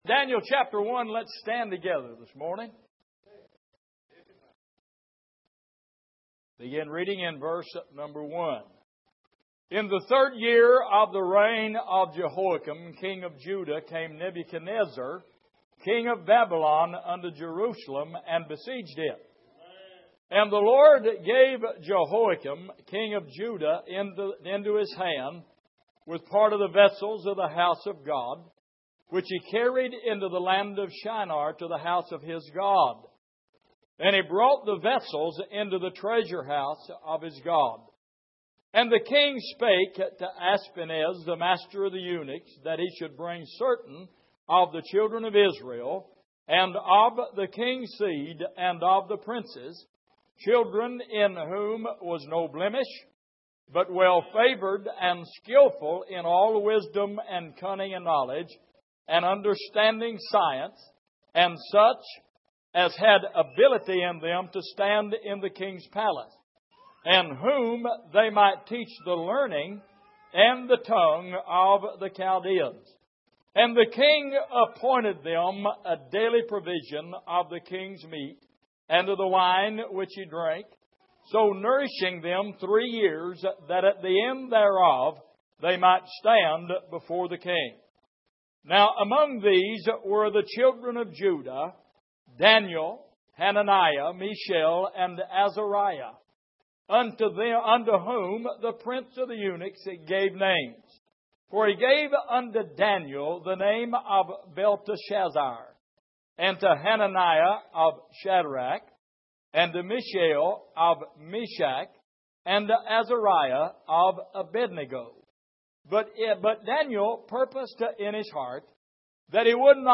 Passage: Daniel 1:1-4 Service: Sunday Morning